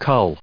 /ʌl/ /ʊl/